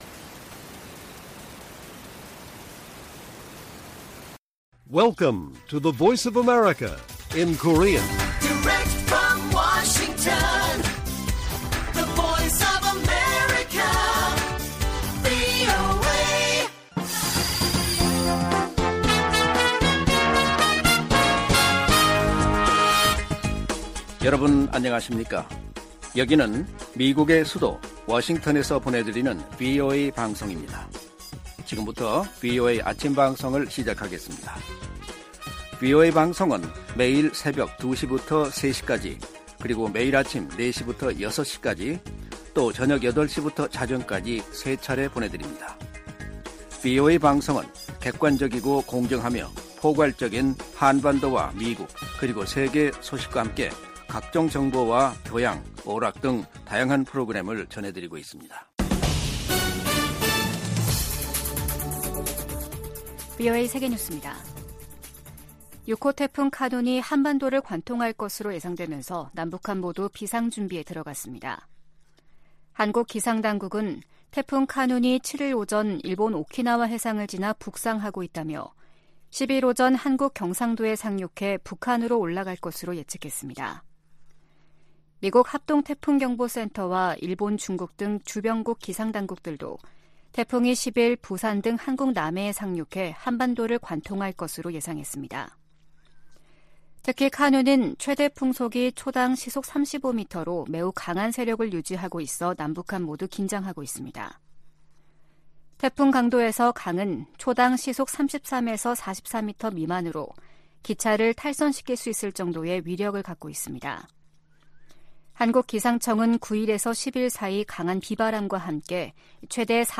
세계 뉴스와 함께 미국의 모든 것을 소개하는 '생방송 여기는 워싱턴입니다', 2023년 8월 8일 아침 방송입니다.